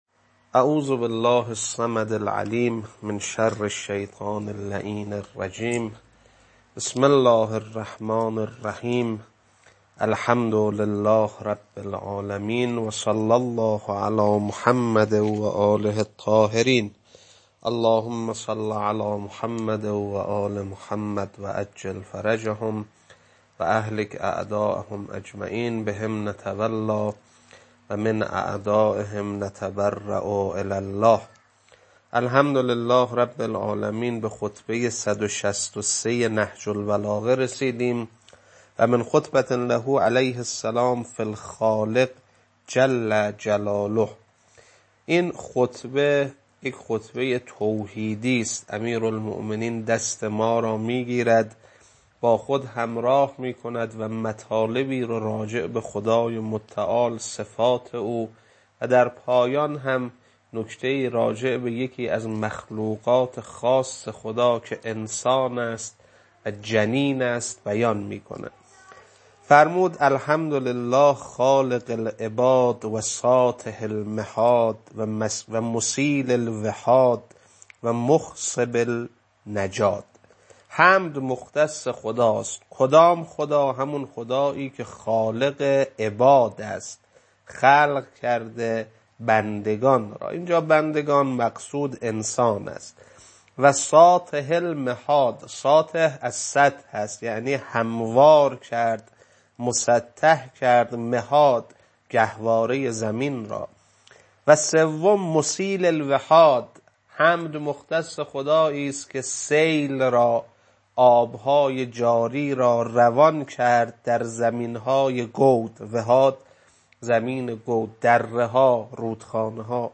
خطبه-163.mp3